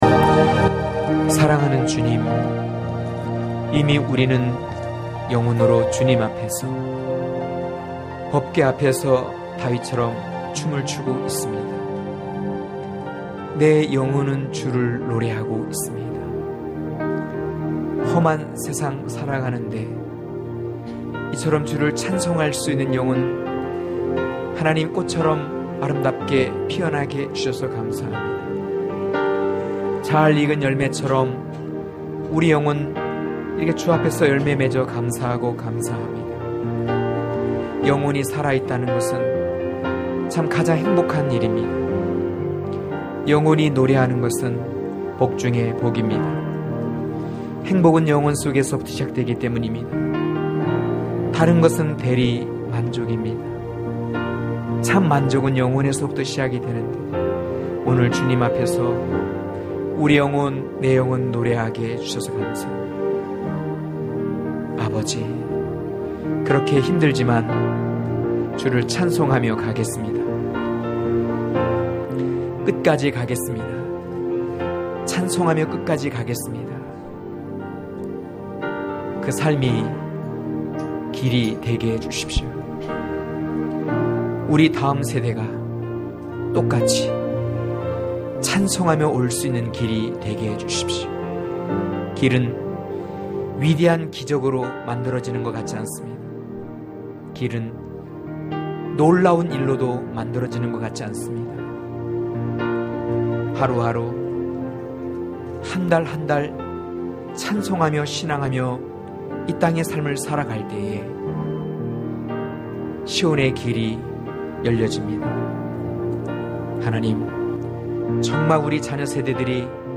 강해설교 - 15.아..